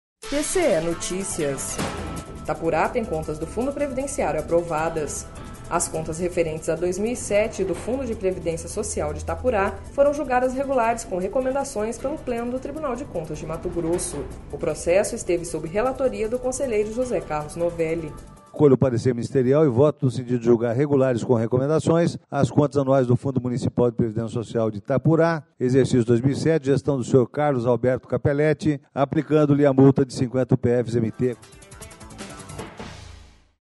Sonora: José Carlos Novelli – conselheiro TCE-MT